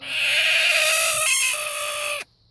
Chicken Scream - Roar
Category 🐾 Animals
chicken creature dinosaur hen roar scream screech sound-effect sound effect free sound royalty free Animals